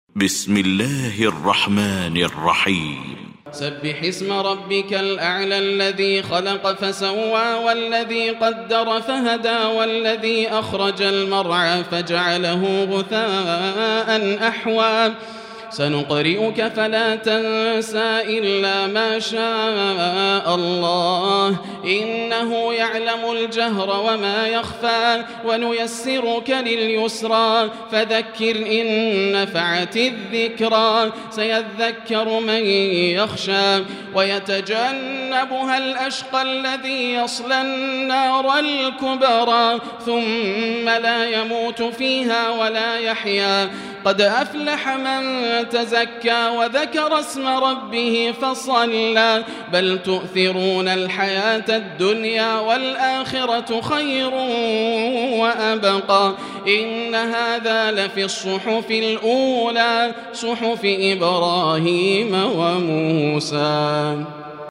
المكان: المسجد الحرام الشيخ: فضيلة الشيخ ياسر الدوسري فضيلة الشيخ ياسر الدوسري الأعلى